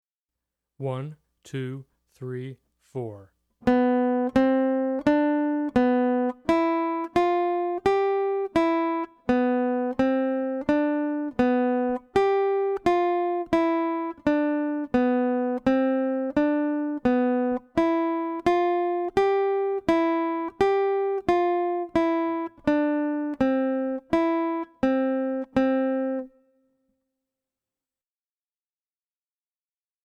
Voicing: Guitar